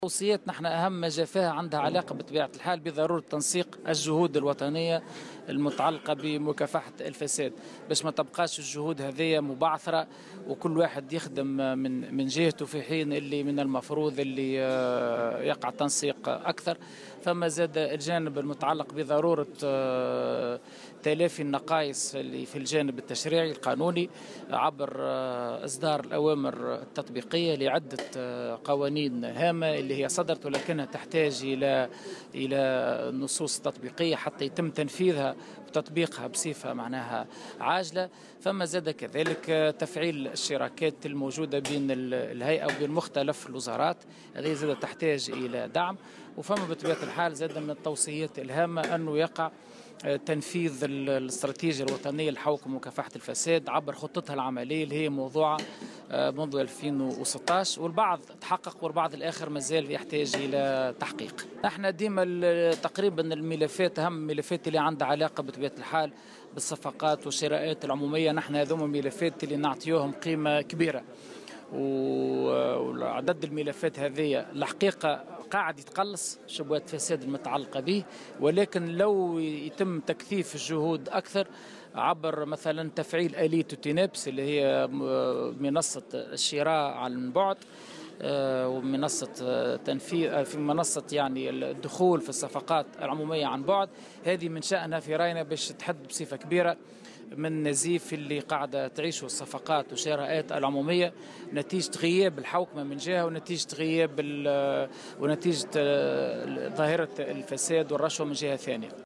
وشدّد في تصريح اليوم لـ"الجوهرة أف أم" على هامش لقاء مفتوح نظمته الهيئة بالشراكة مع الإتحاد الجهوي للمرأة والإتحاد الجهوي للشغل والفرع الجهوي للمحامين والفرع الجهوي للرابطة التونسية للدفاع عن حقوق الإنسان بسوسة حول تقريرها لسنة 2017 على ضرورة تكاثف الجهود الوطنية المتعلّقة بمكافحة الفساد.